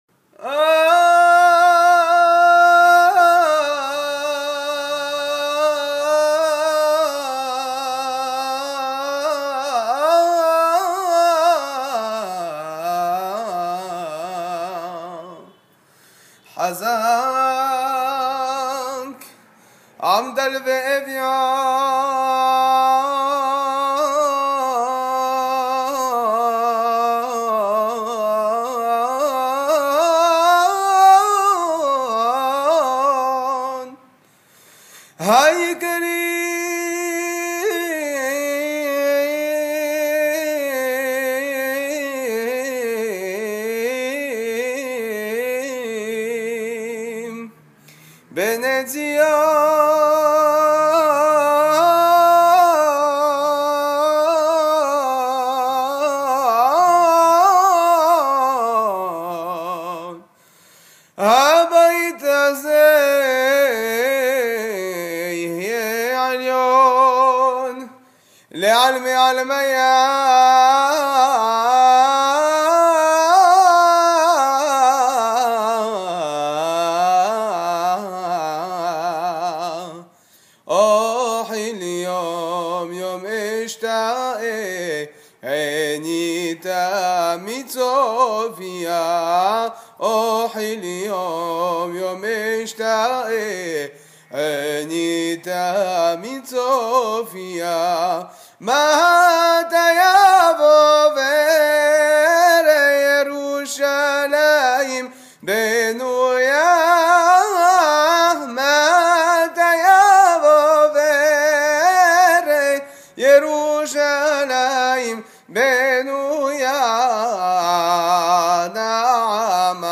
Hazanout Makam Hijjaz.
Makam Hijaz